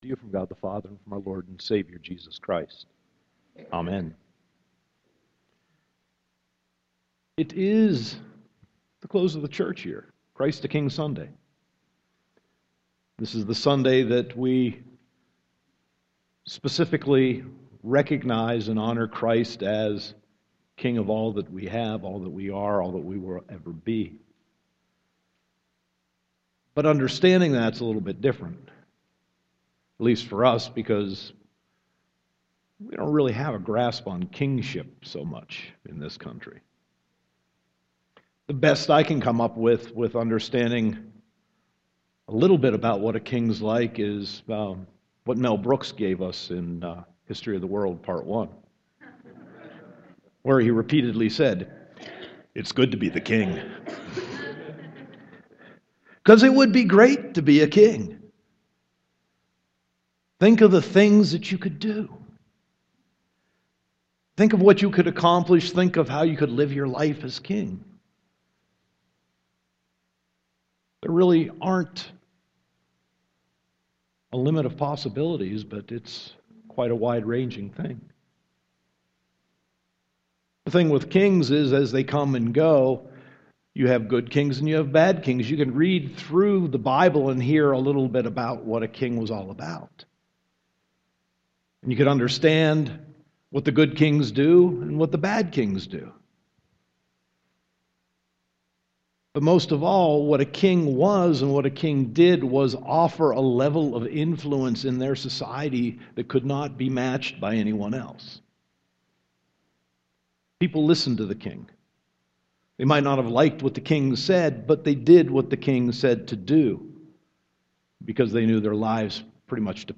Sermon 11.23.2014